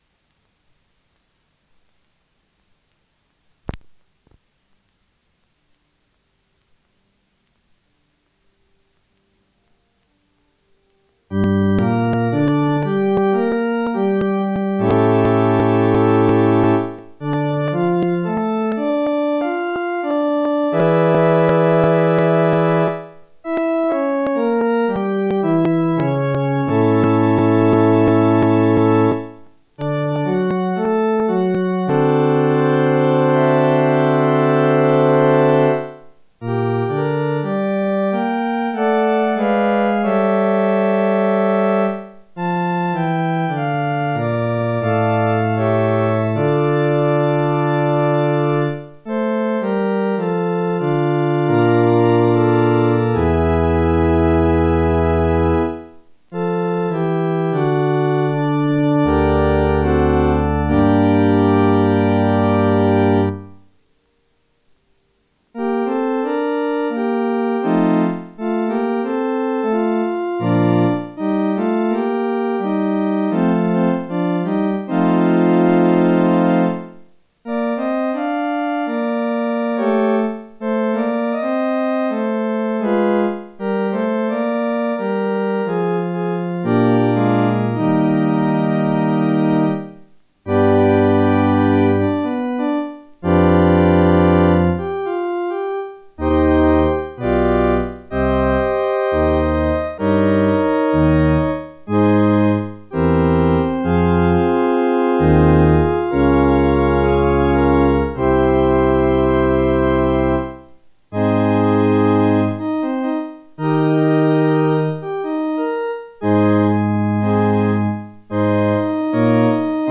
Piezas para órgano: 54.- Toccata Nº 2 (
toccata_n2.mp3